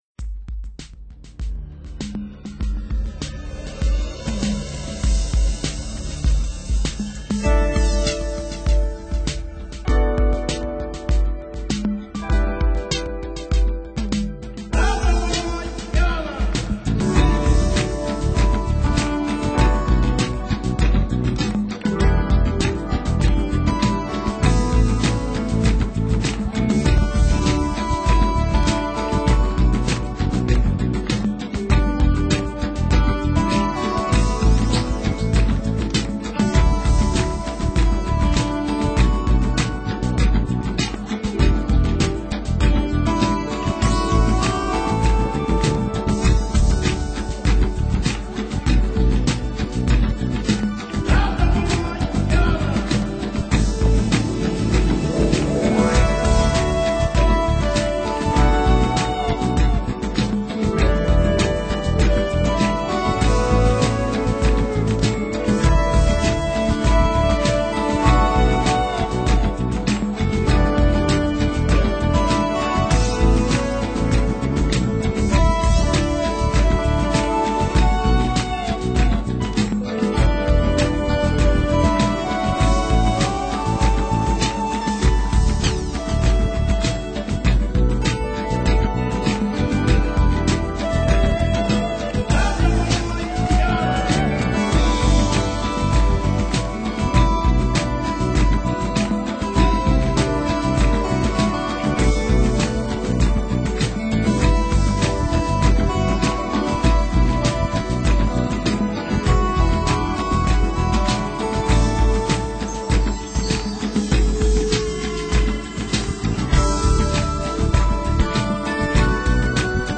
歌声与自然的风声、潮声相伴随，跟着音乐走，尽情享受